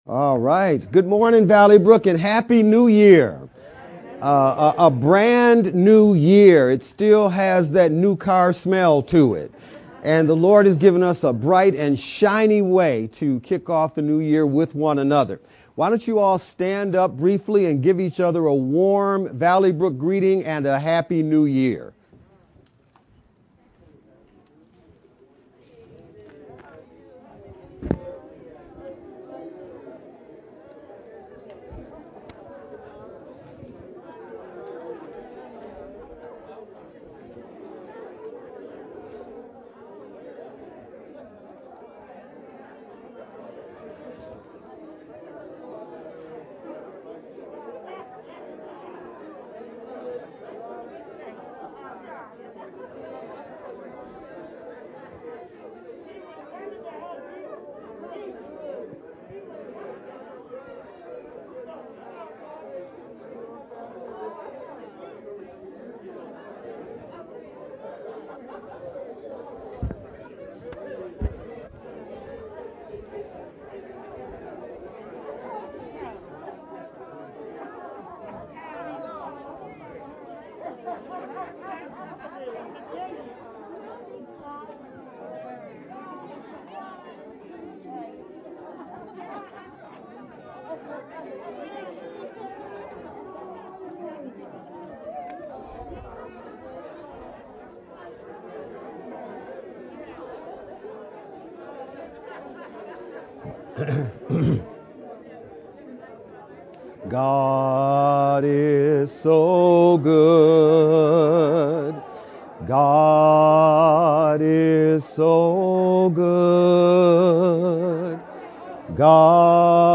VBCC-Sermon-edited-sermon-only-CD.mp3